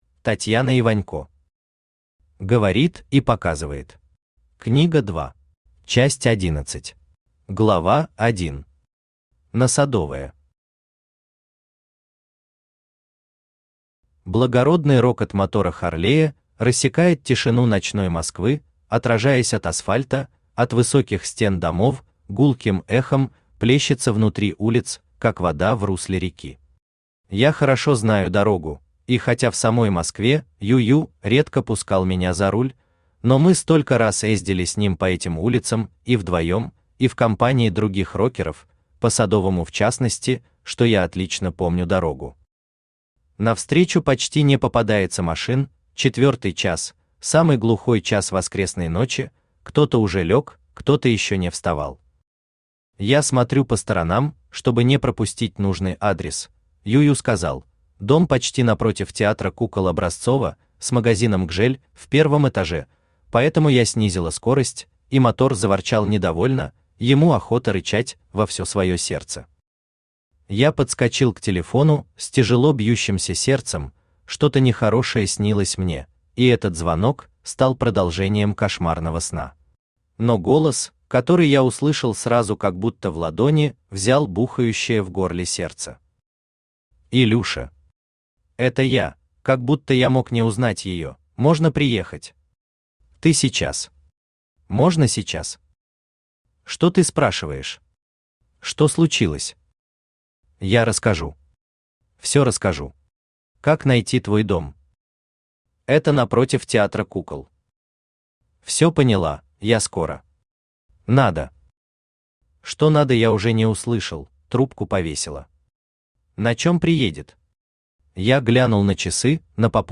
Книга 2 Автор Татьяна Вячеславовна Иванько Читает аудиокнигу Авточтец ЛитРес.